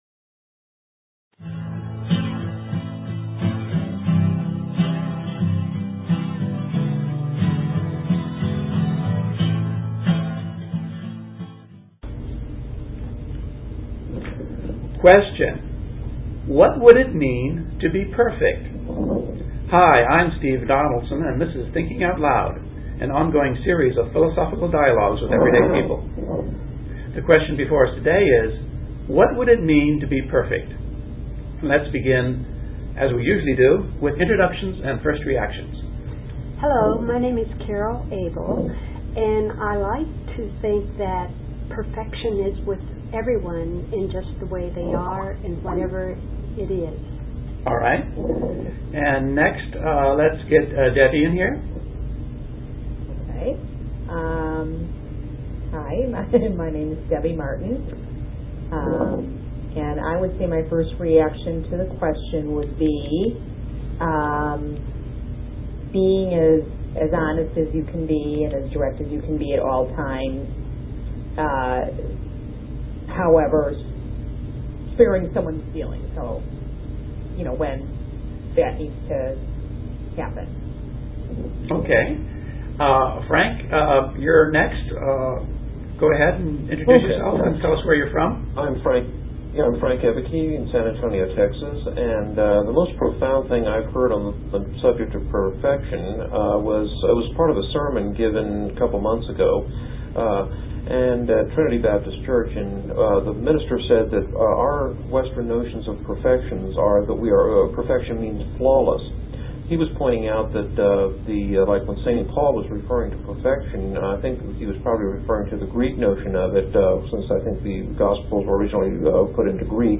We had, for the first time, more cyber guests than local participants.